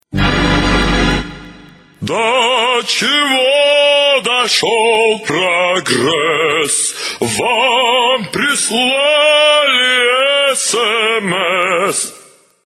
Главная » Рингтоны » SMS рингтоны